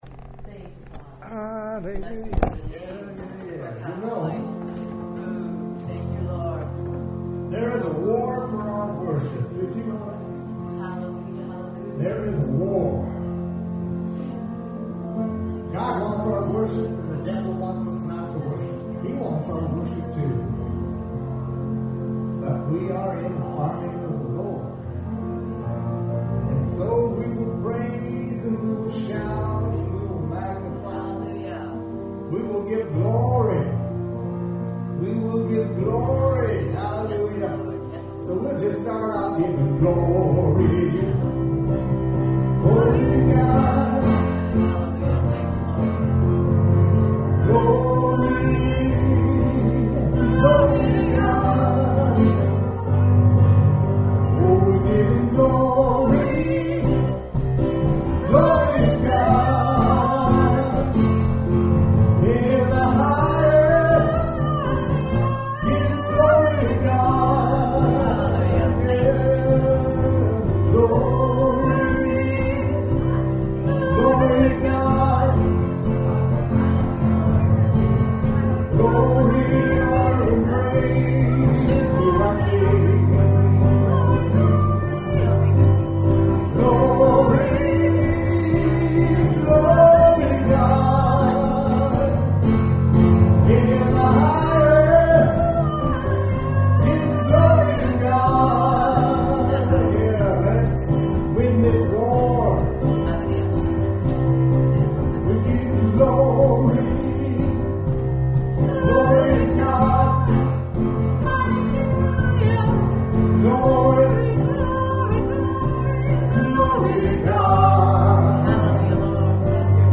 WORSHIP 720.mp3